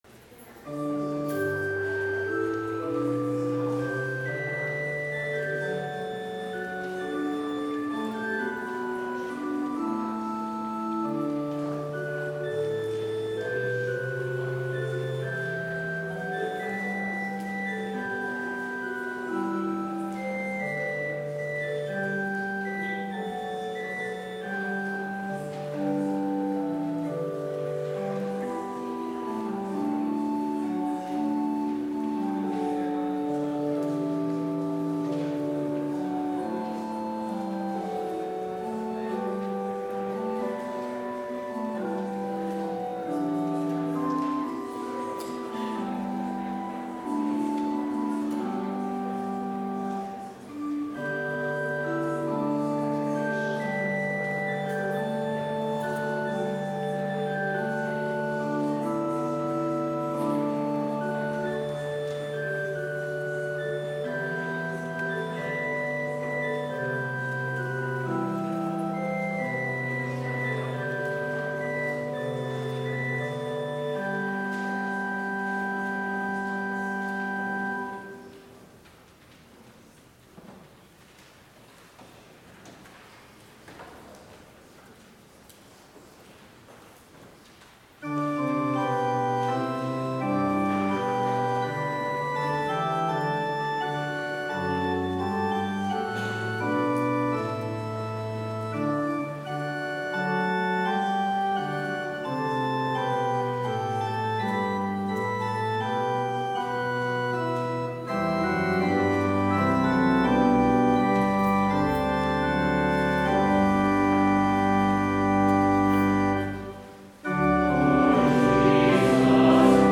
Complete service audio for Chapel - March 17, 2022
Order of Service Prelude Hymn 258 - Lord Jesus Christ, My Savior Blest